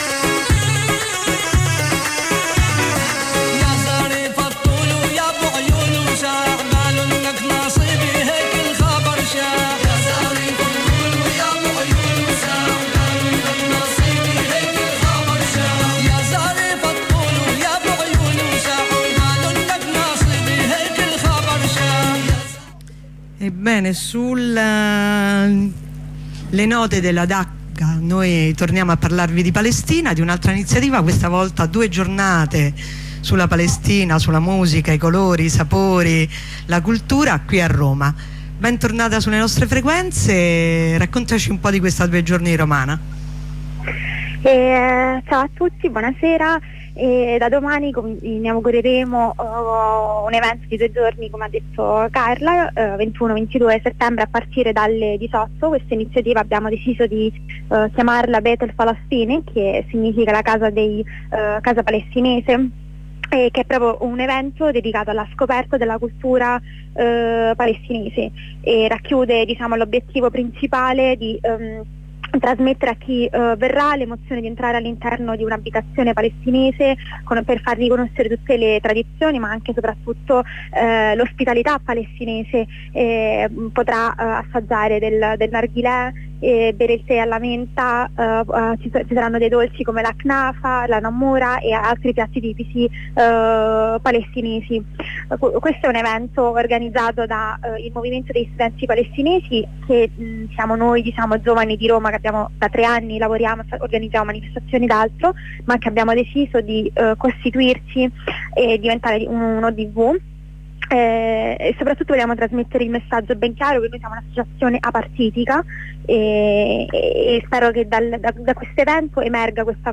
Corrispondenza con un'avvocata del Legal Team, dopo la mattinata di identificazioni